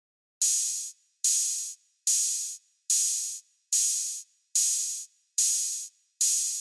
Defy This Open Hat 145bpm
描述：陷阱和科幻的结合。沉重的打击和神秘感。使用这些循环来制作一个超出这个世界范围的爆炸性节目吧 :)D小调
Tag: 145 bpm Trap Loops Percussion Loops 1.11 MB wav Key : D